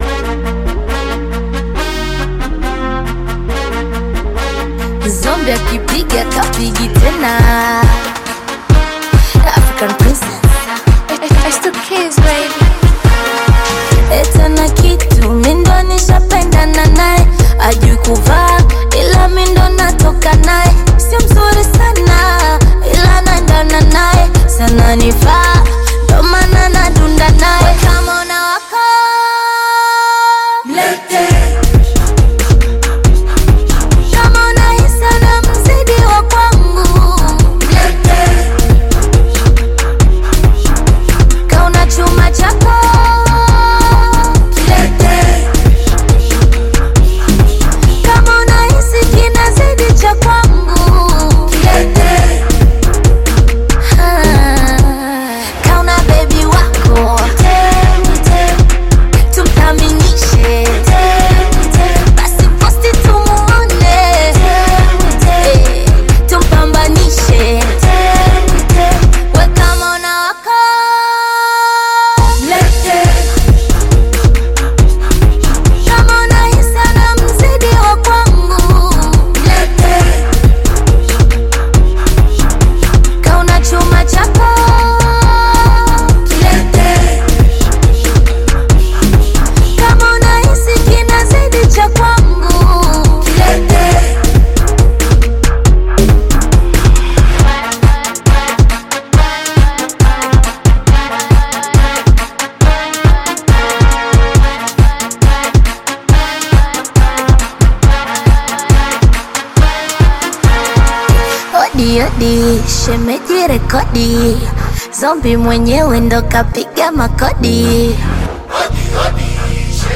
Bongo Flava Club Banger music